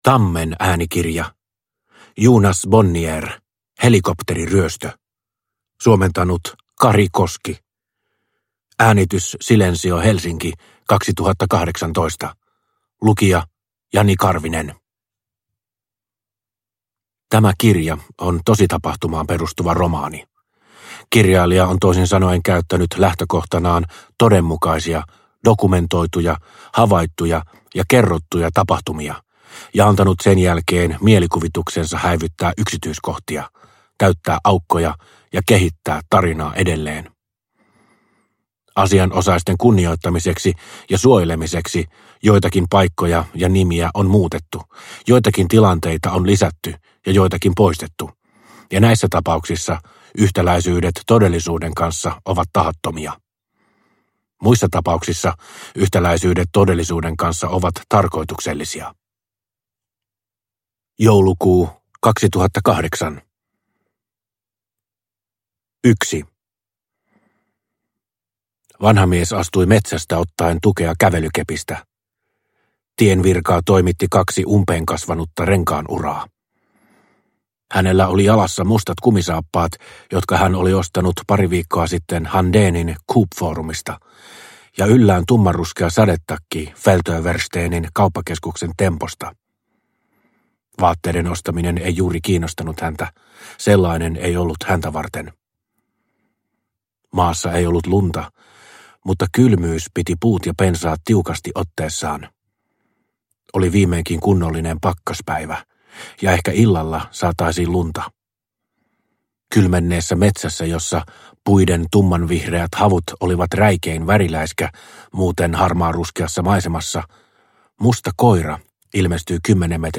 Helikopteriryöstö – Ljudbok – Laddas ner